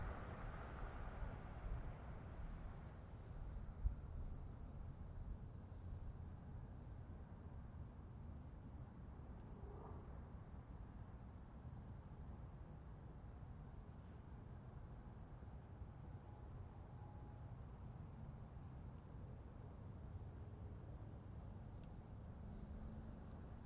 环境噪音 " 夜晚的声音 1
描述：晚上在德克萨斯州桑代尔的蟋蟀和汽车稍微响亮。
标签： 氛围 晚上 蟋蟀 国家 性质 现场录音
声道立体声